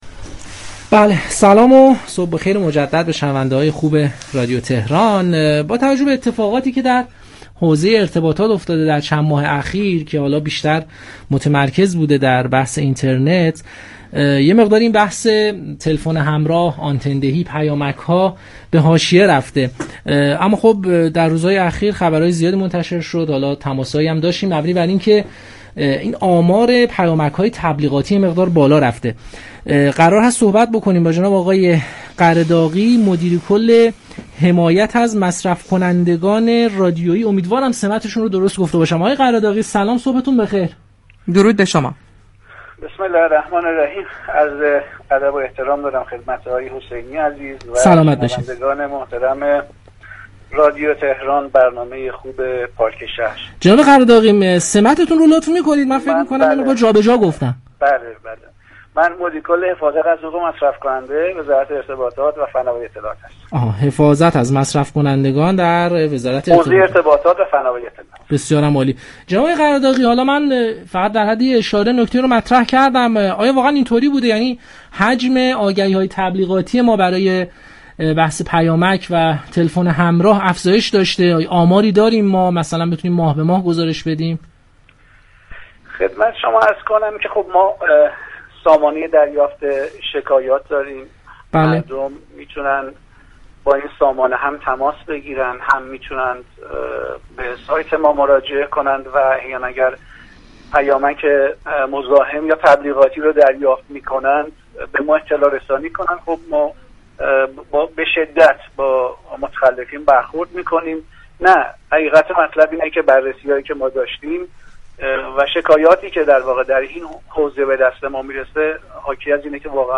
به گزارش پایگاه اطلاع رسانی رادیو تهران، پیمان قره داغی مدیركل حفاظت از حقوق مصرف‌كننده وزارت ارتباطات و فناوری اطلاعات در خصوص چگونگی ارسال پیامك‌های تبلیغاتی، در گفتگو با پارك شهر رادیو تهران گفت: مشتركین می‌توانند جهت ثبت شكایت از پیامك‌های انبوه مزاحم و تبلیغاتی شخصی، شماره مورد نظر را به سرشماره رایگان 195 پیامك كنند ما به‌شدت با متخلفین برخورد می‌كنیم.